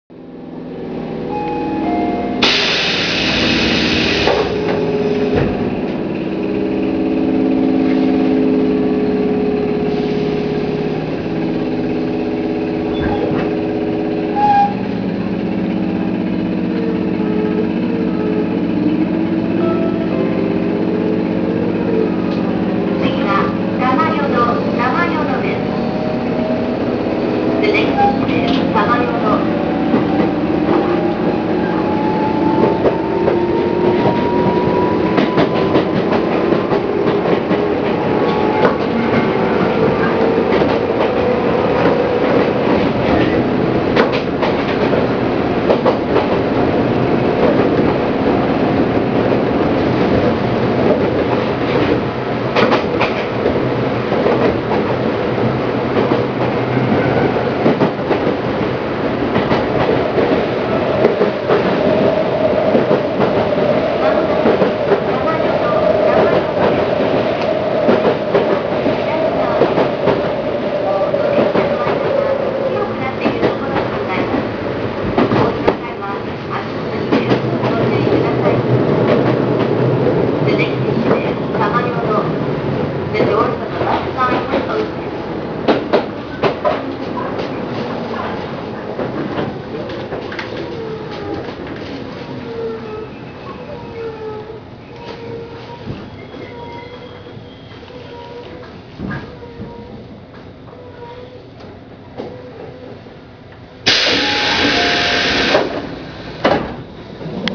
・8000系(ワンマン対応車)走行音
【東上線】鉢形→玉淀（1分47秒：585KB）…81107Fにて。
ワンマン対応車はドアチャイムと車内自動放送が特徴。元々の扉の音が大きいので、ドアチャイムが殆ど意味をなしていないのはご愛嬌。